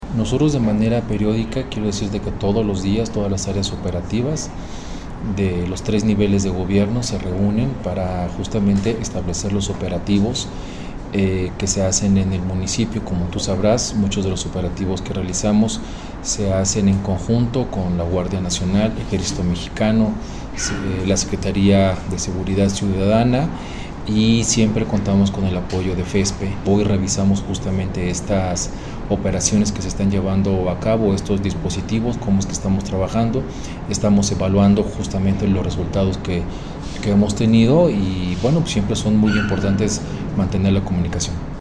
AudioBoletines
Luis Ricardo Benavides Hernádez, secretario de seguridad ciudadana